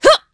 Isolet-Vox_Attack3.wav